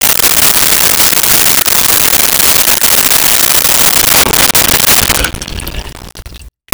Sink 03
Sink 03.wav